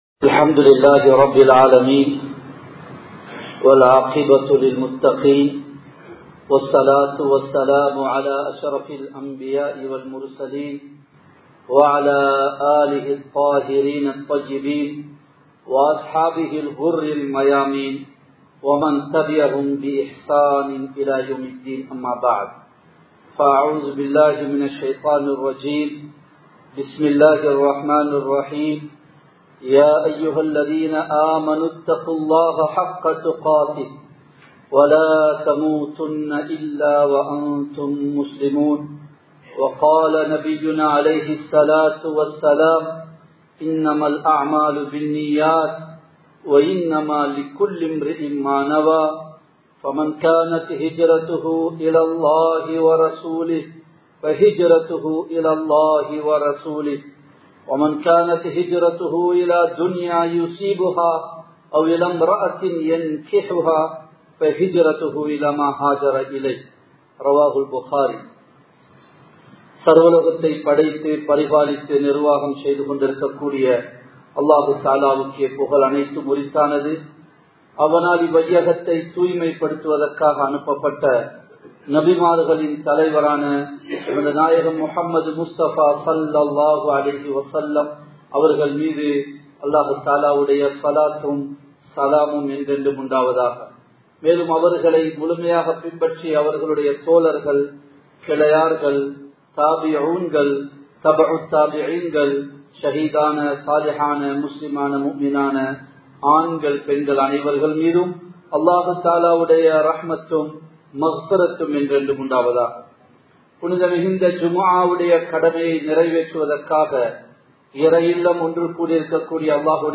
இறைவனை நெருங்குவோம் | Audio Bayans | All Ceylon Muslim Youth Community | Addalaichenai
Borella Jumua Masjith